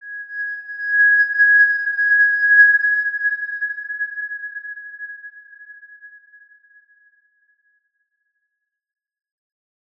X_Windwistle-G#5-ff.wav